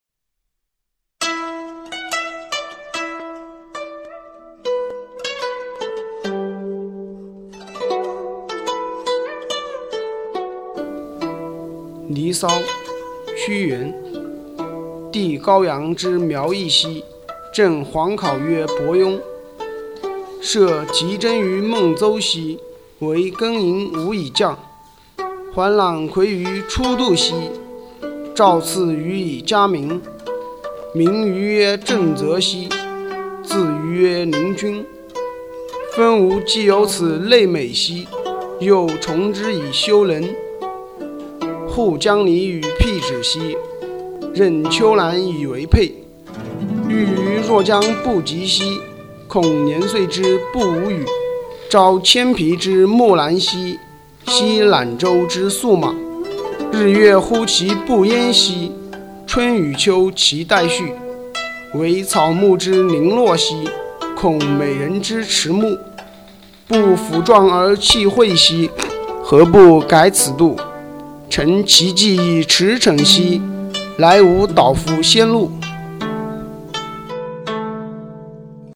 屈原《离骚》全文与译文（含配乐朗读）　/ 屈原